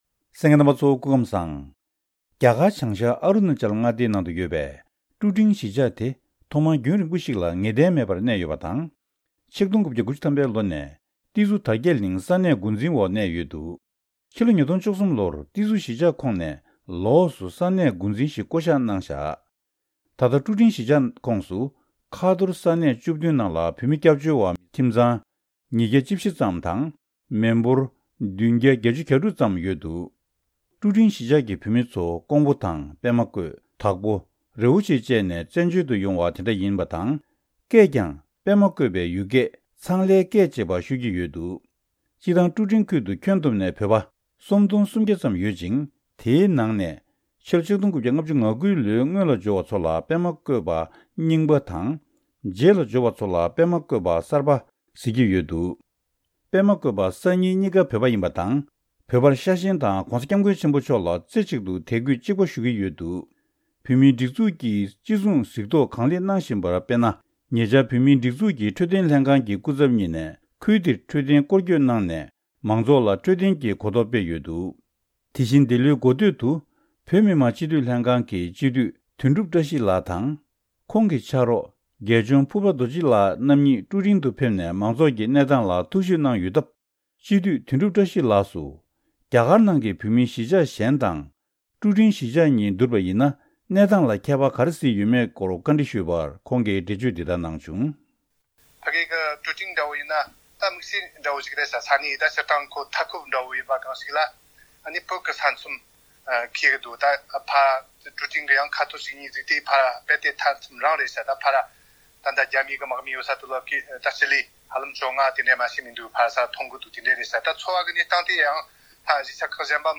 བཀའ་འདྲི་ཞུས་ནས་ཕྱོགས་སྒྲིགས་ཞུས་པ་ཞིག་གསན་རོགས་གནང་།།